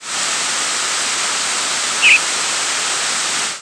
Tanager species
presumed tanager species nocturnal flight call